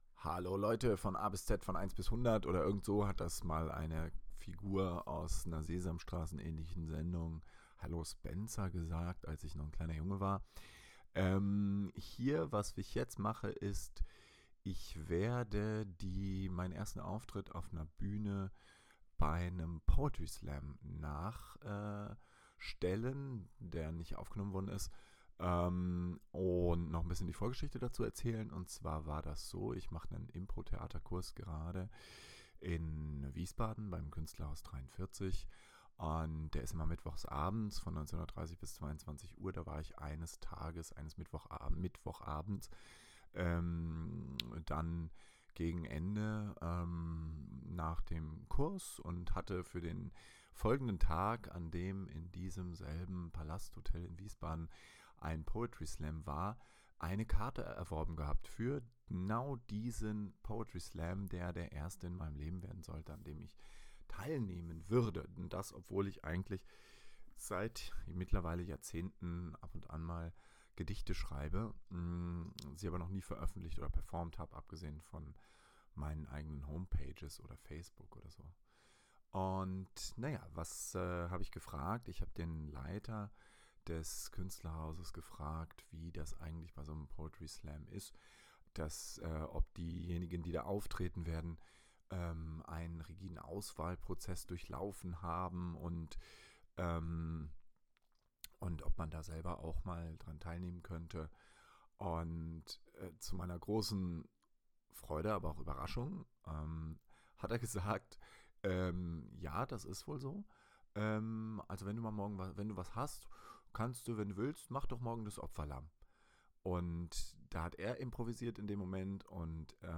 Poesie
Erster Auftritt Kleinkunstbühne kunestlerhaus43, 26.09.2024 Poetry Slam als „Opferlamm“ [Beispiel für die Jury, Proberunde]: ReisenBildet